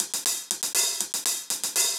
Index of /musicradar/ultimate-hihat-samples/120bpm
UHH_AcoustiHatA_120-02.wav